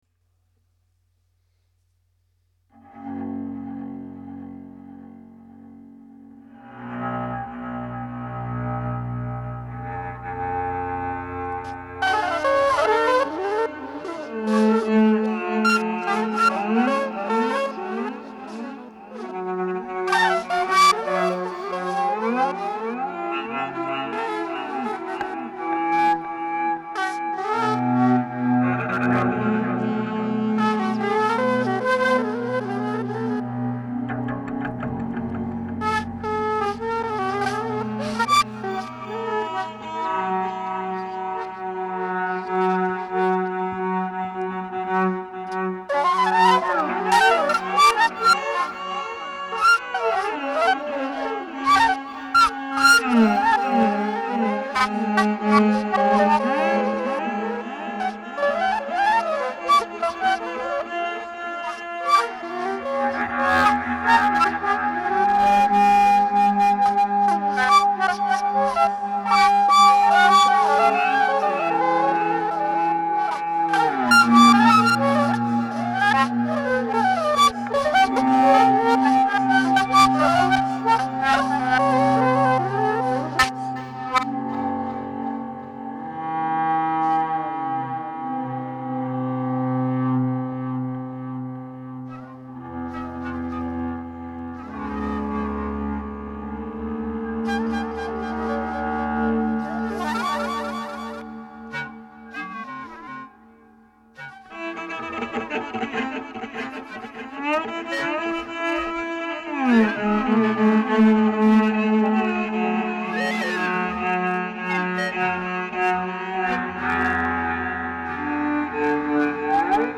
L’audio è la sintesi di un intervento di due ore.